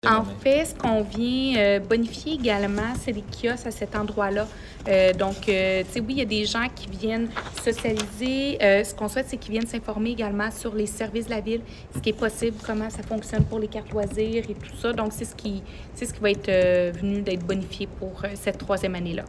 La mairesse de Granby, Julie Bourdon :